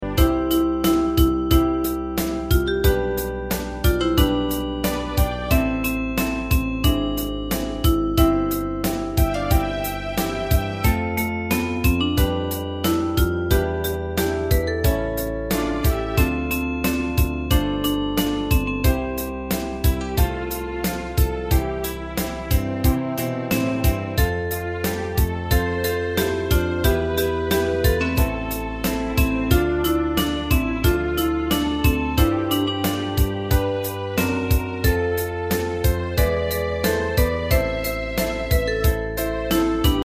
大正琴の「楽譜、練習用の音」データのセットをダウンロードで『すぐに』お届け！
Ensemble musical score and practice for data.